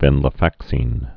(vĕnlə-făksēn, -sĭn)